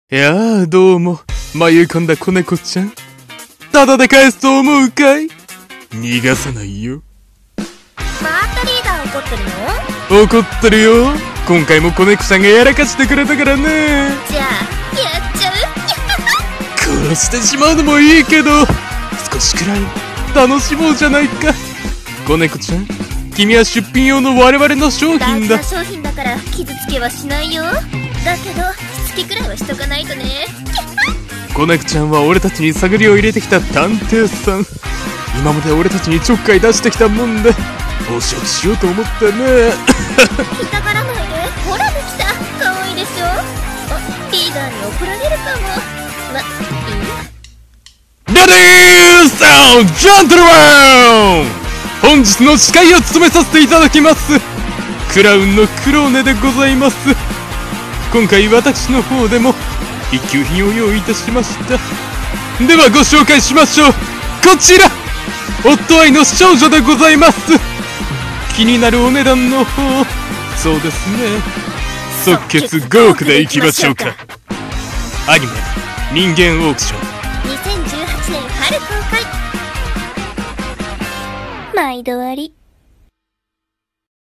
CM風声劇】人間オークション 【起-ｷ-】